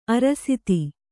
♪ arasiti